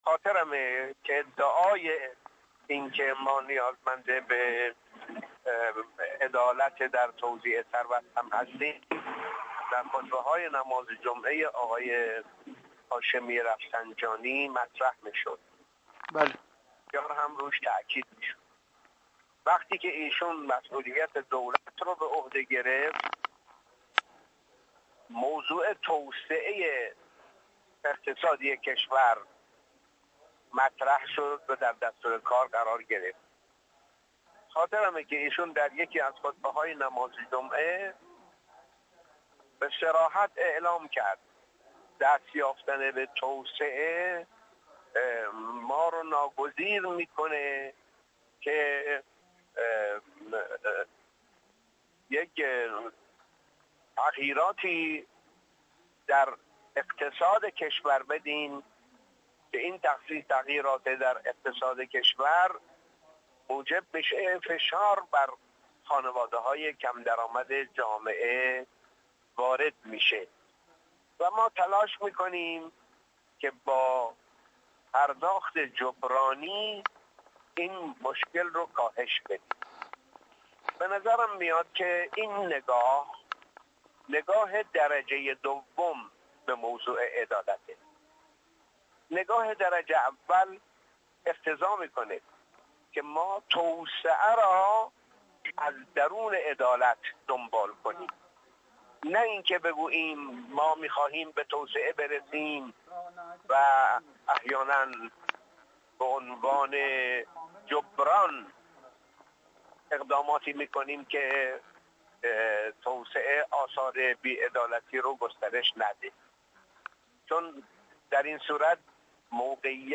حجت‌الاسلام والمسلمین غلامرضا مصباحی مقدم، عضو مجمع تشخیص مصلحت نظام، در گفت‌وگو با ایکنا، درباره عوامل دخیل در عقب‌ماندگی جمهوری اسلامی در حوزه عدالت گفت: عدالت به عنوان یک هدف بسیار مهم در دستور کار اکثر دولت‌ها نبوده است.